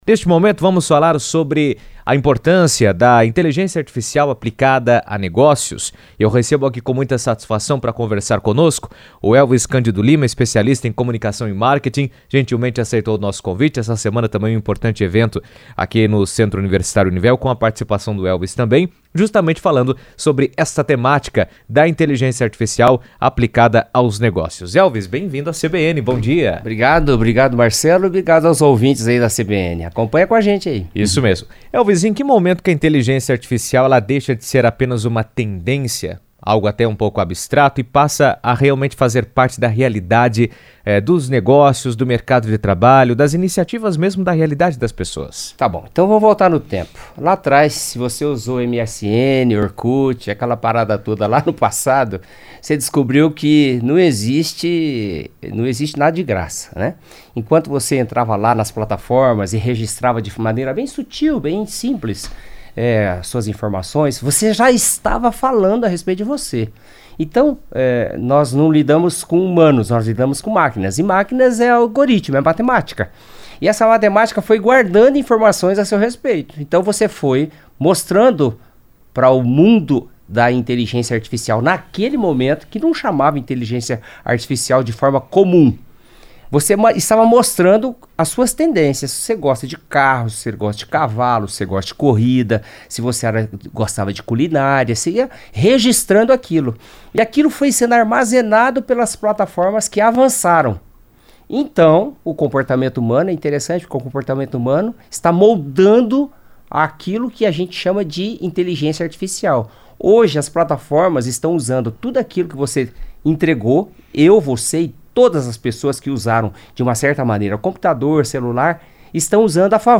A aplicação da inteligência artificial vem remodelando a dinâmica dos negócios, ao ampliar a capacidade de análise de dados, automatizar processos e impulsionar estratégias mais precisas e competitivas. Em entrevista à CBN